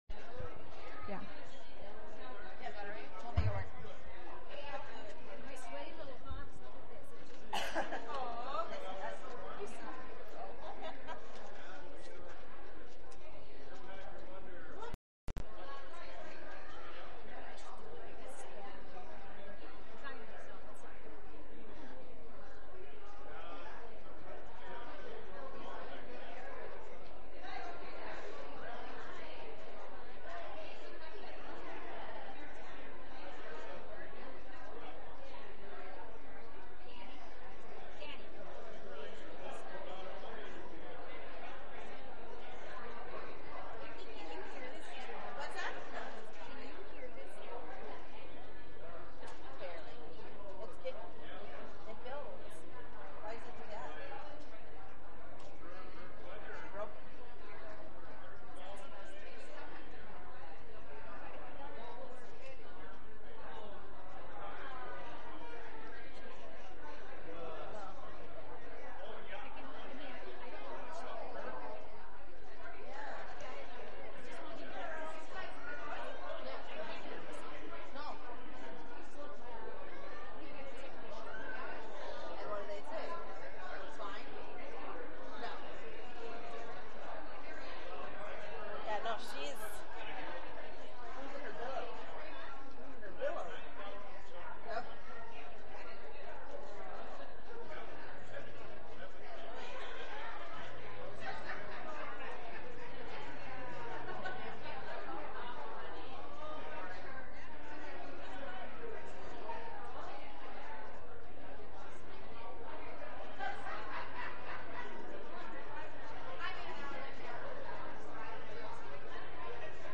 From Series: "Sunday Worship"
Sunday-Service-5-22-22.mp3